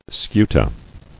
[scu·ta]